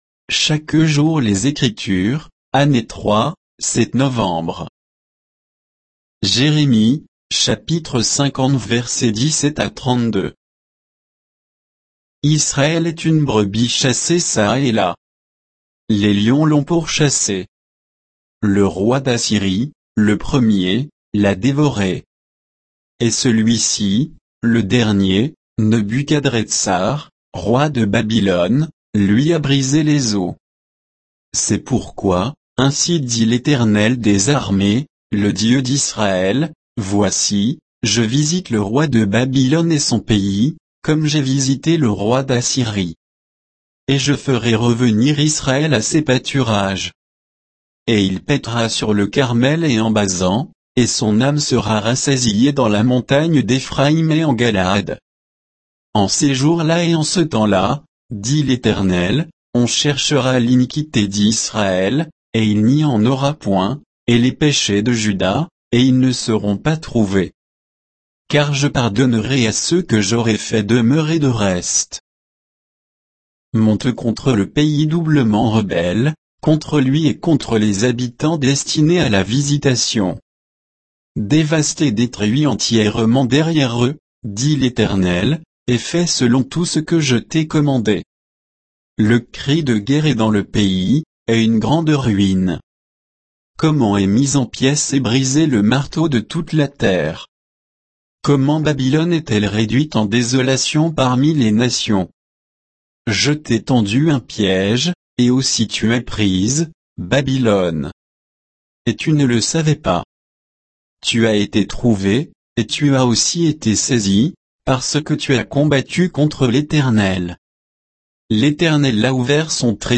Méditation quoditienne de Chaque jour les Écritures sur Jérémie 50